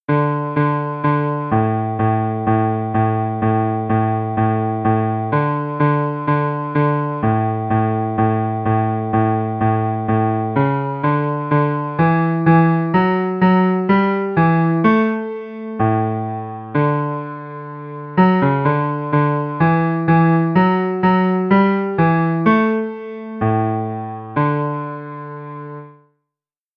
Piano recordings (computer generated) for the chorus: